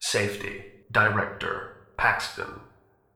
scientist18.ogg